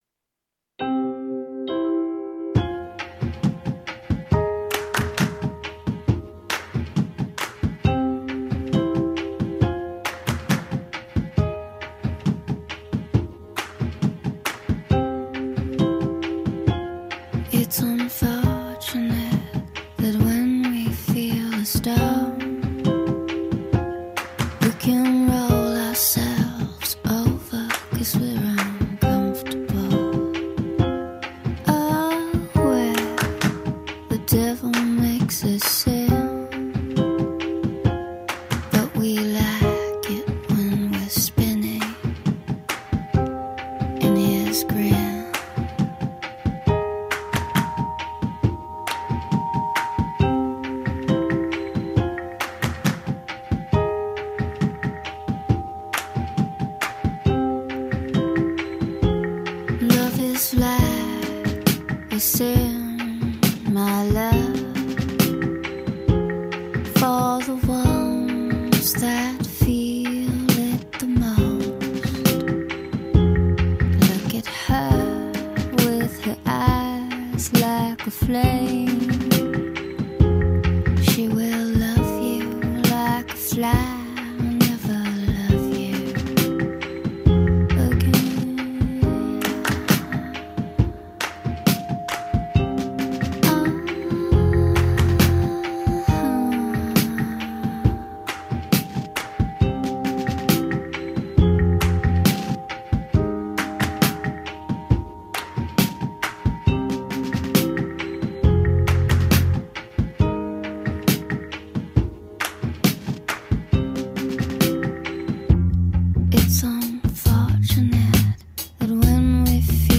Category: Down Tempo